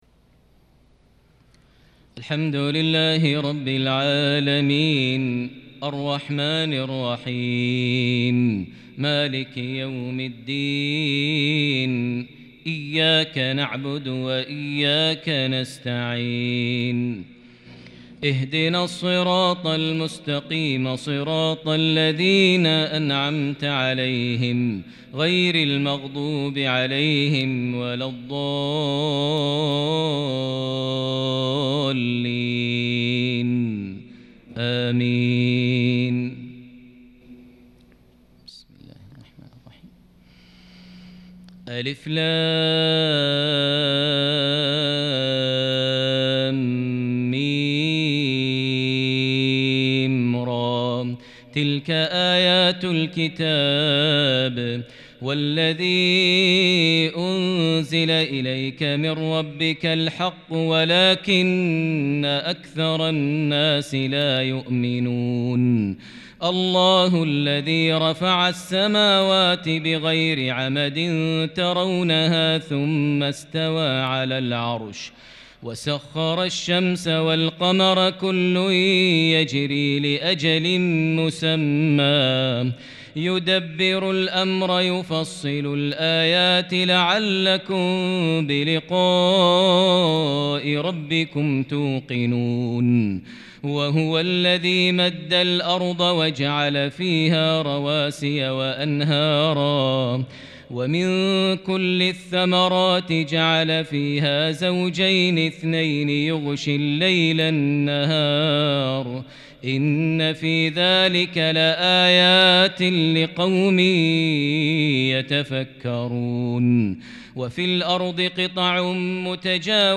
عشائية بديعة بالكرد الفذ فواتح سورة الرعد | الثلاثاء 24 ذي الحجة 1442هـ > 1442 هـ > الفروض - تلاوات ماهر المعيقلي